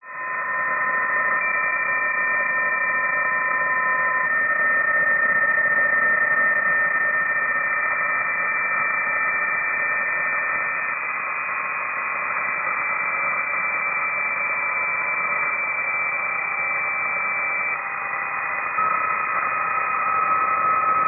Here are the WAV files he generated with Doppler changing at